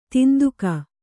♪ tinduka